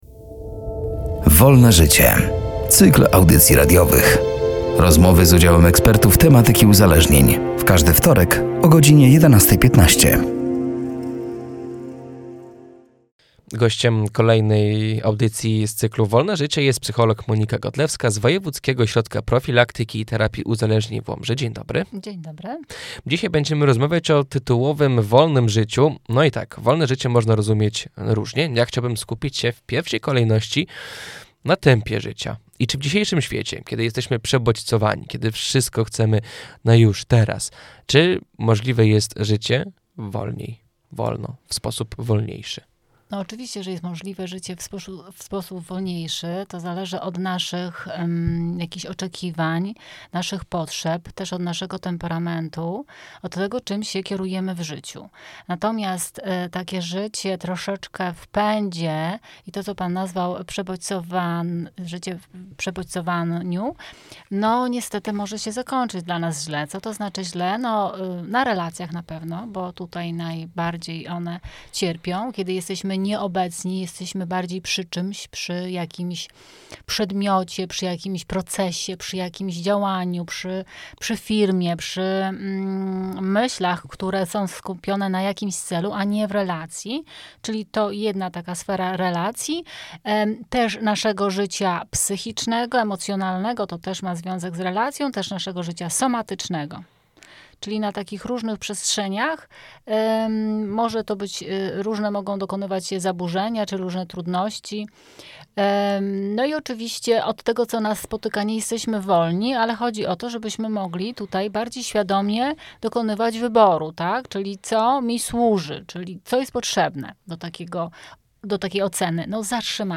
„Wolne życie” to cykl audycji radiowych. Rozmowy z udziałem ekspertów z obszaru psychologii i uzależnień.